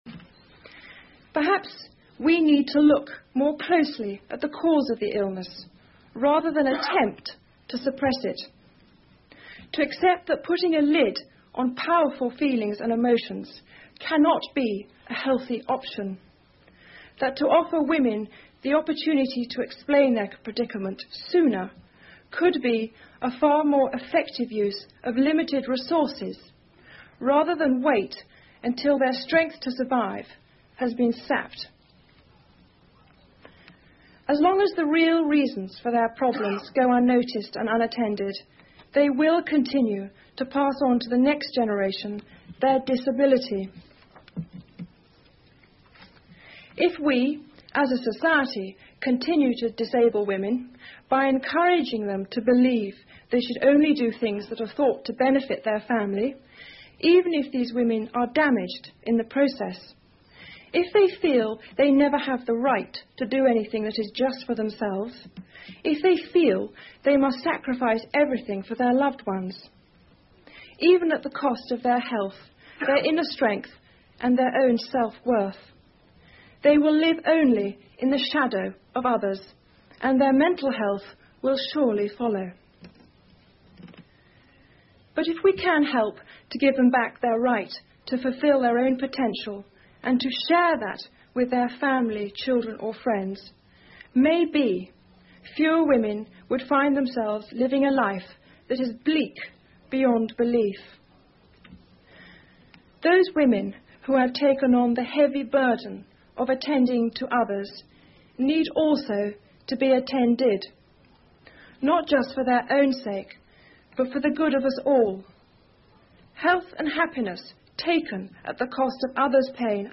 英文演讲录 戴安娜王妃：女性必须是“超人”才合格吗？(3) 听力文件下载—在线英语听力室